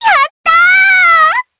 Hanus-Vox_Happy4_jp.wav